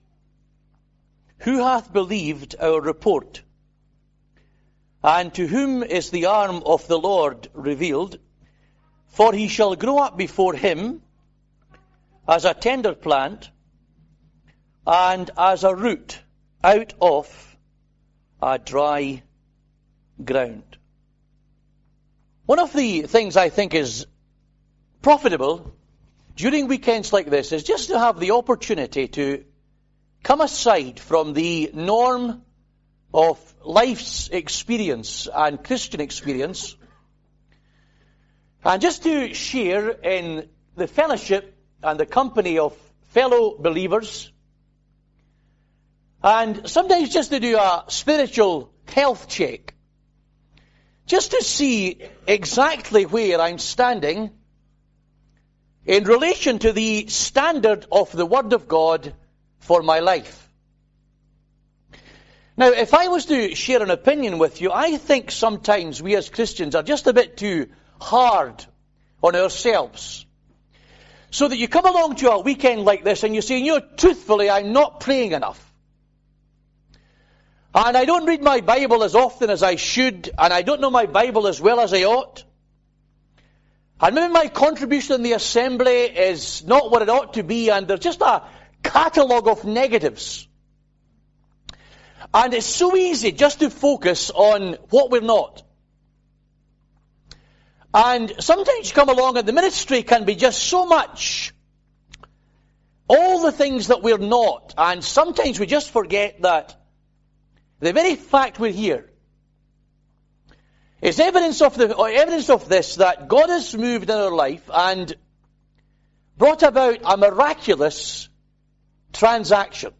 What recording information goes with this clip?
(Recorded in Northampton, 24th Aug 2007)